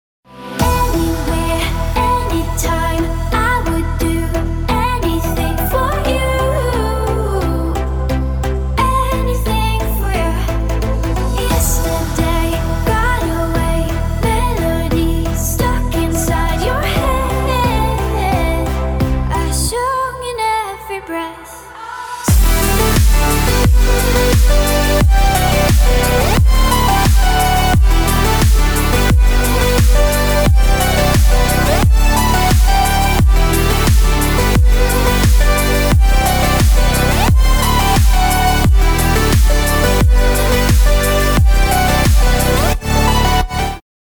• Качество: 320, Stereo
спокойные
красивый женский голос
микс